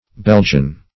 Belgian \Bel"gi*an\, a.